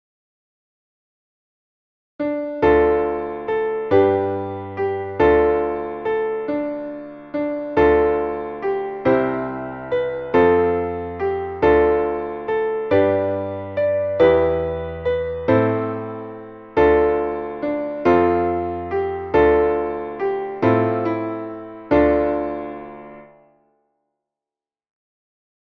Traditionelles Winter-/ Frühlingslied